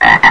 00107_Sound_cwack.mp3